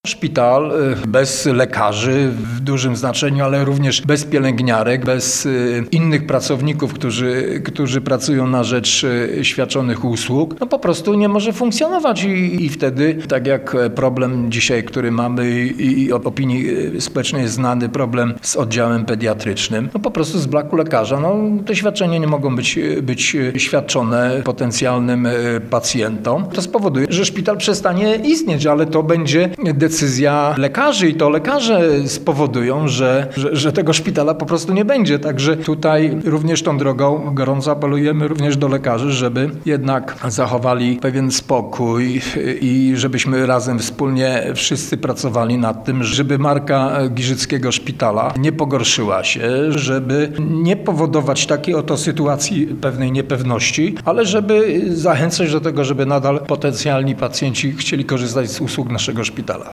– Jeżeli lekarze odejdą, spowoduje to utratę możliwości świadczenia usług i może skutkować poważnymi konsekwencjami dla pacjentów – podkreśla starosta.